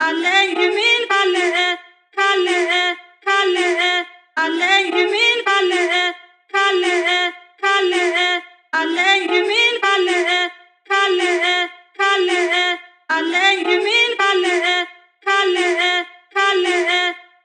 Each sample is meticulously crafted to help you achieve the same hard-hitting and soulful vibe that Divine is known for.
Gully-Loops-Tribe-Melody-Loop-BPM-110-F-Min.wav